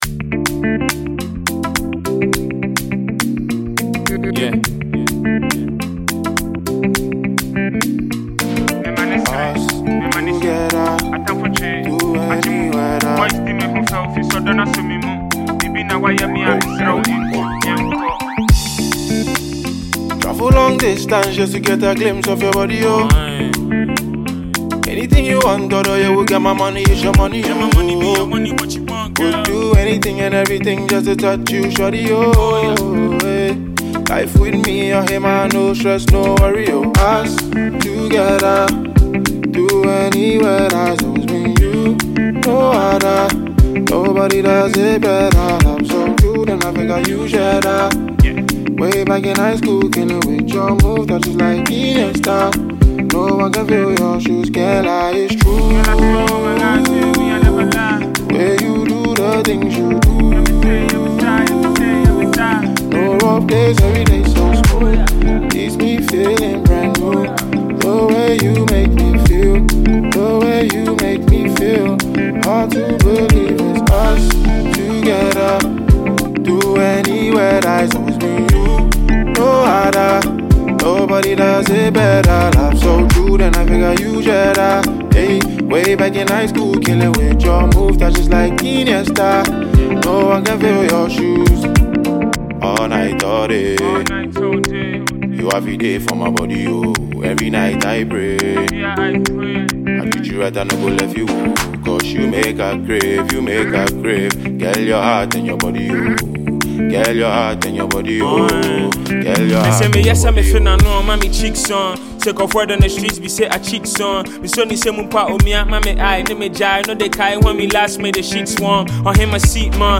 very groovy afrobeats joint